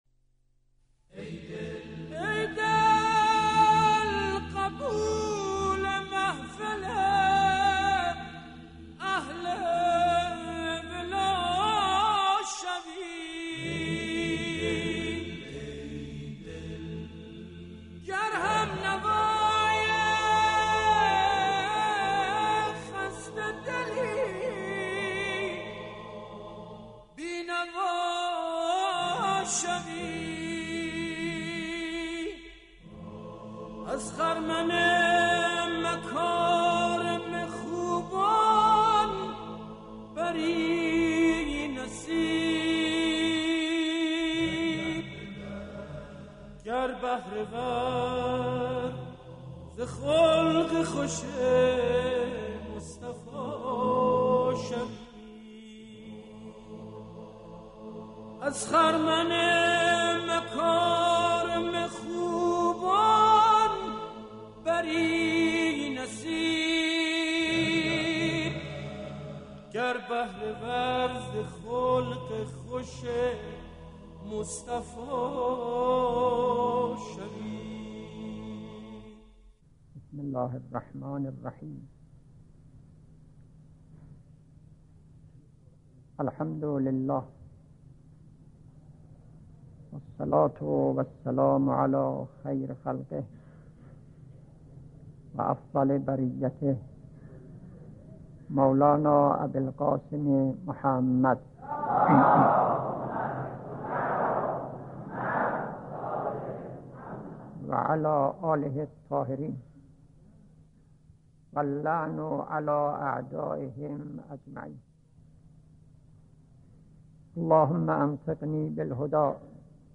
/ مدت سخنرانی : 25 دقیقه